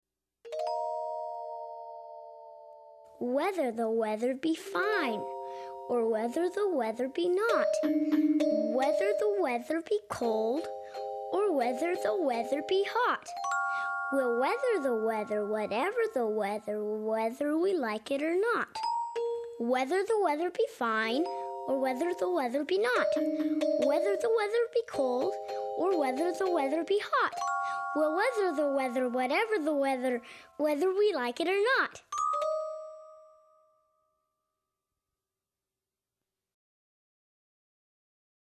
英语童谣